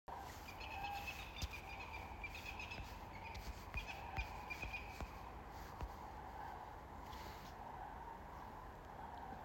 Zvirbuļvanags, Accipiter nisus
StatussDzirdēta balss, saucieni